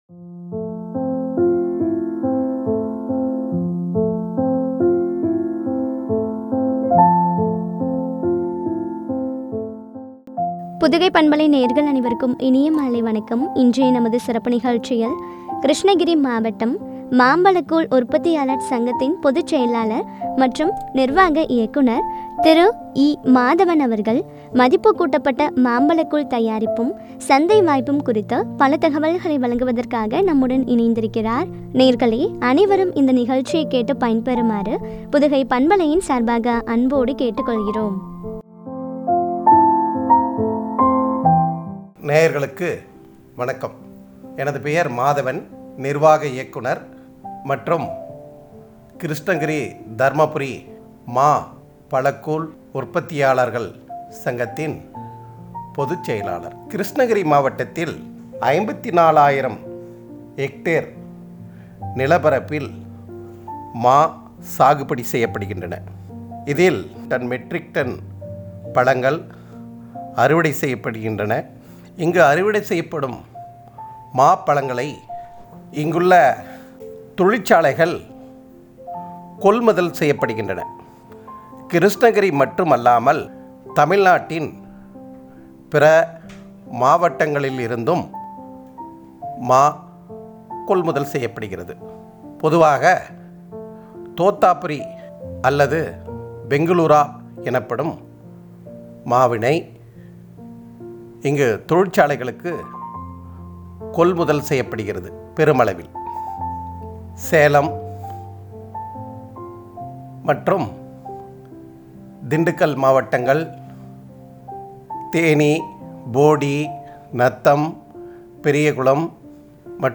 சந்தை வாய்ப்பும் பற்றிய உரையாடல்.